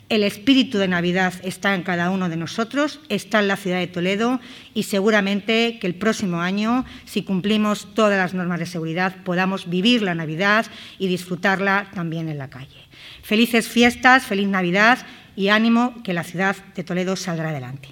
Milagros Tolón, alcaldesa de Toledo